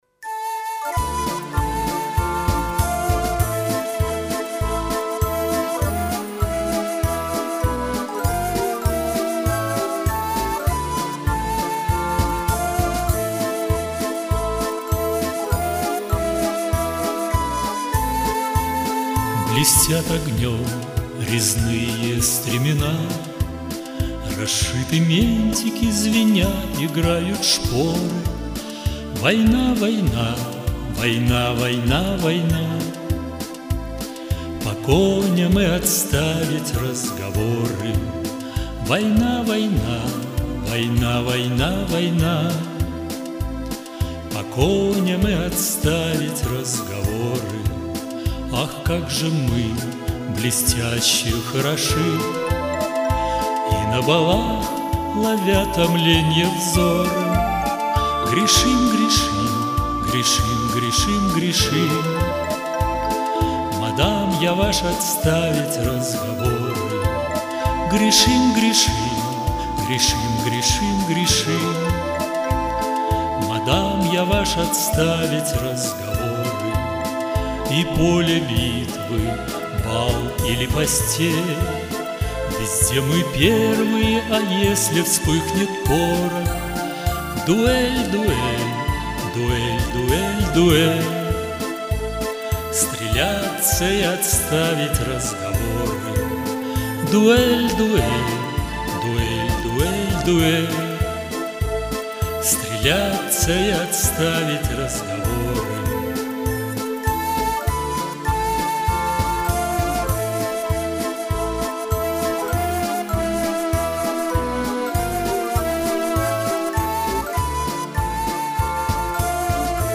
Авторская песня
Гусарский марш-2(+).mp3